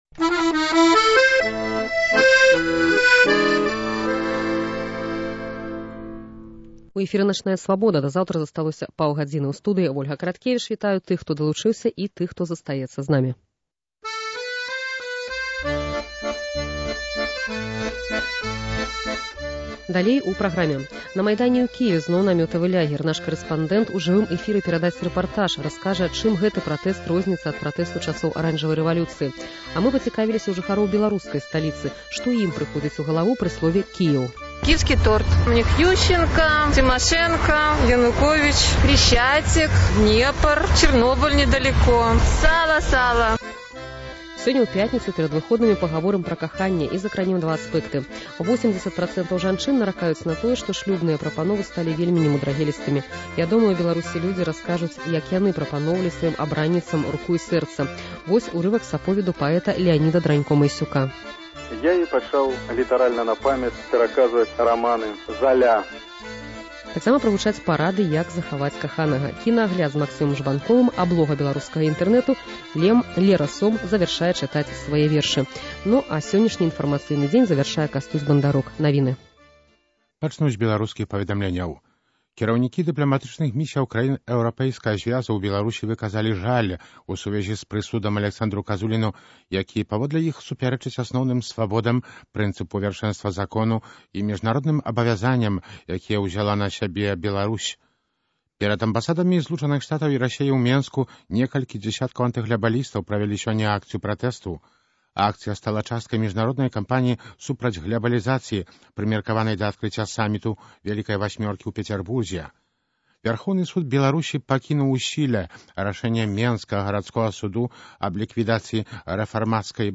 Жывы рэпартаж зь Кіеўскага Майдану, дзе зноў намётавы лягер